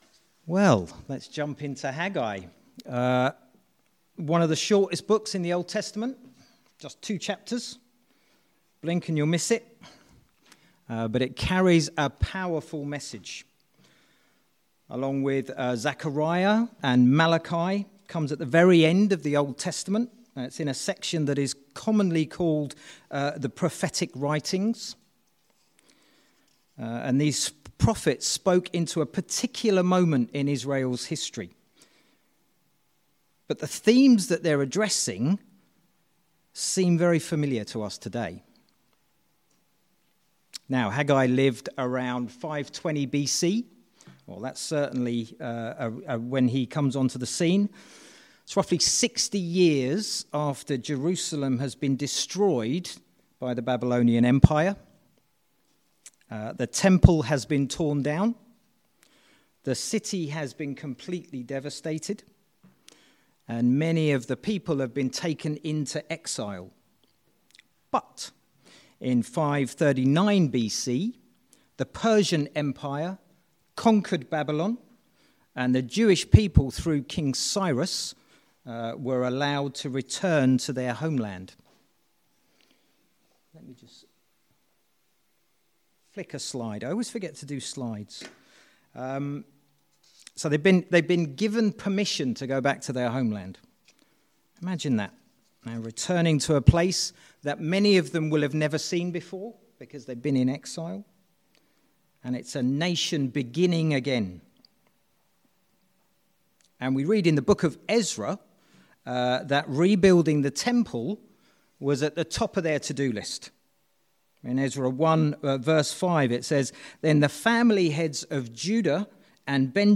Sermons 2026